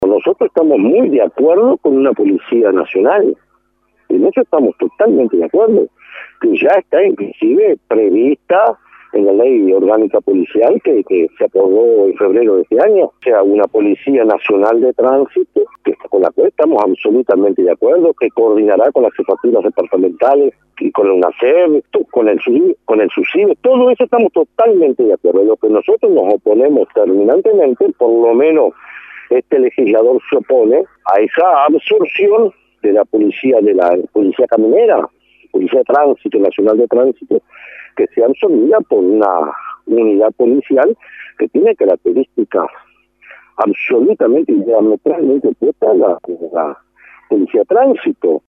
Escuche a Mier